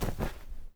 glider_close.wav